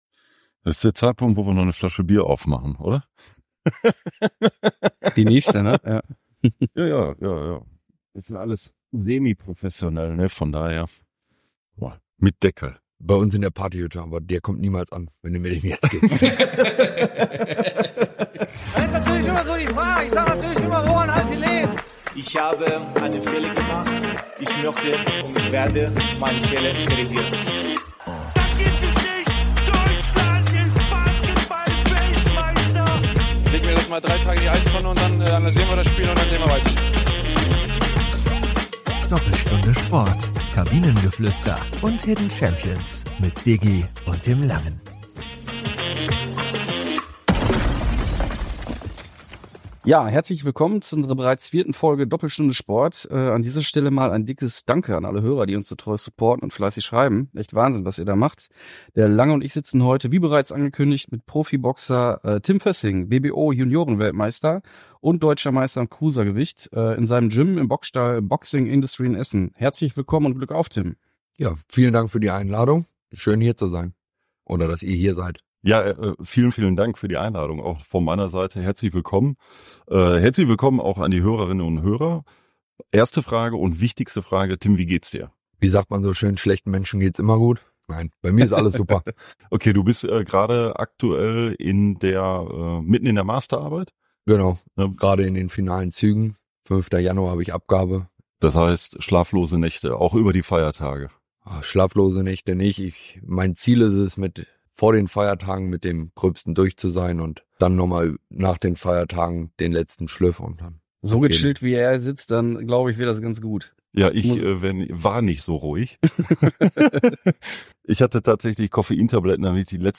Für unsere neue Folge Doppelstunde Sport haben wir es uns im Gym der Boxing Industry in Essen gemütlich gemacht.